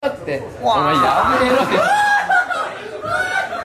water_tRiuoul.mp3